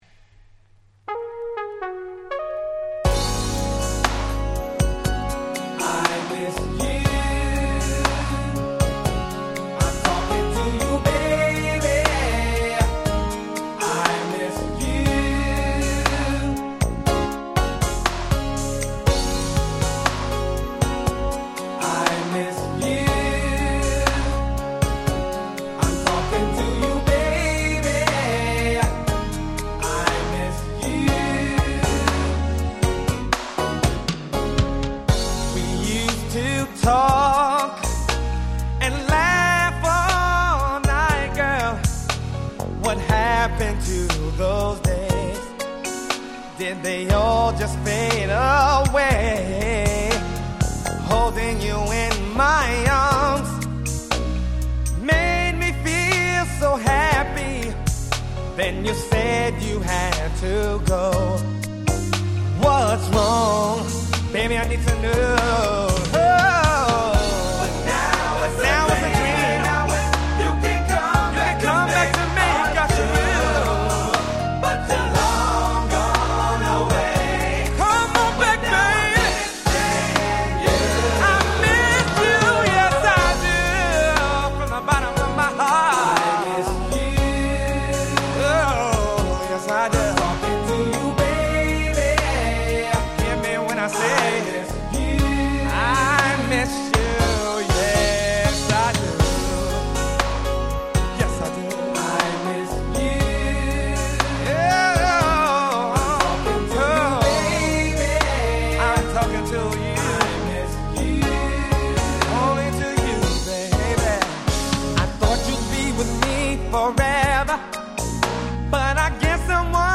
93' Nice R&B/New Jack Swing LP !!
A面はハネ系New Jack Swing中心、B面は激甘Slow中心。